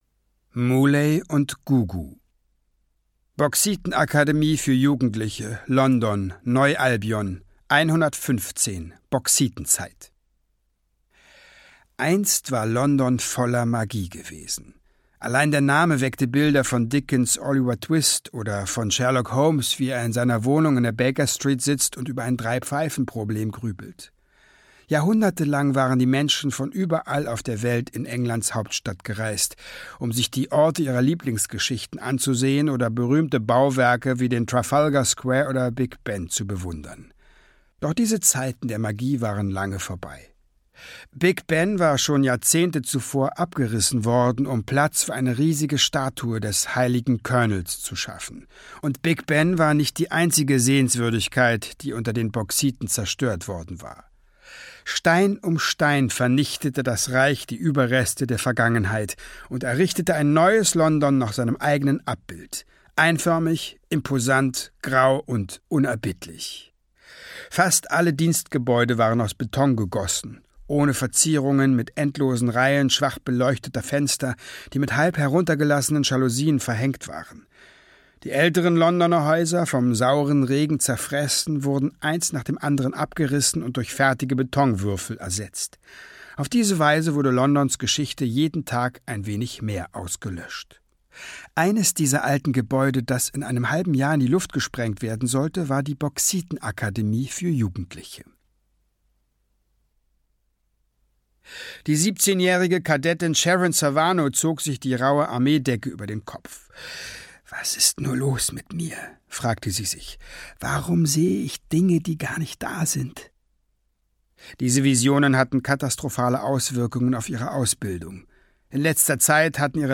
WARP - Der Klunkerfischer - Eoin Colfer - Hörbuch